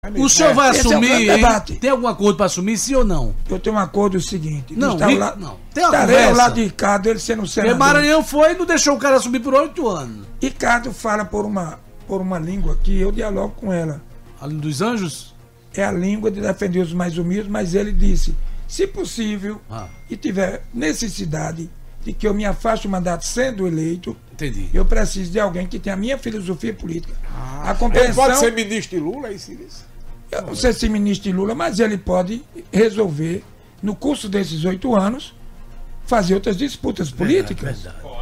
As declarações de Jeová repercutiram em entrevista ao programa Arapuan Verdade.